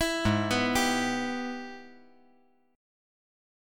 AbmM7#5 Chord
Listen to AbmM7#5 strummed